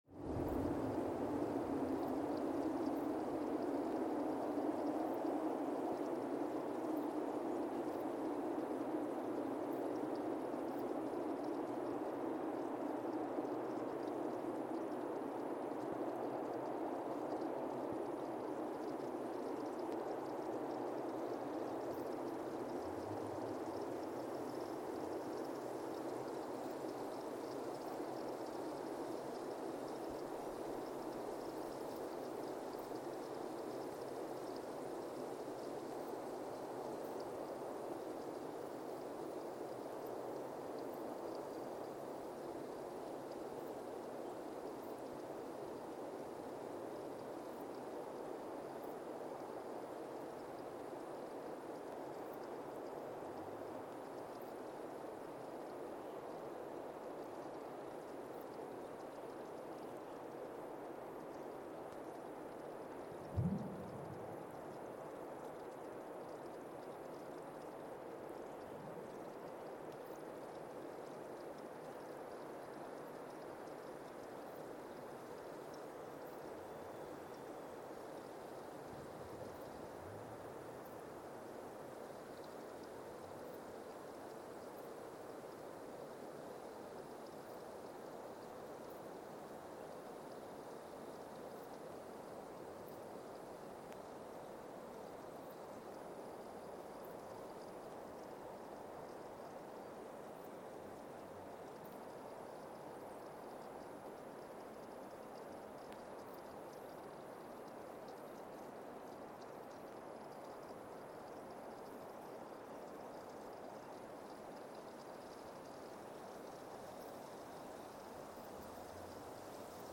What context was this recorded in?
Weston, MA, USA (seismic) archived on December 20, 2024 Station : WES (network: NESN) at Weston, MA, USA Sensor : CMG-40T broadband seismometer Speedup : ×1,800 (transposed up about 11 octaves) Loop duration (audio) : 05:36 (stereo)